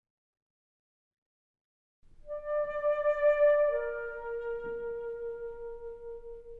An introductory lesson to Vibrato on the Saxophone. Vibrato is the technique that causes your sound to waver in pitch, and makes your sound more warm and listenable.
As you can hear, in the first sample, the player has a very pure, clean sound. In the second sample, the player adds some warmth to the sound by making regular oscillating changes in the pitch.
vibrato.mp3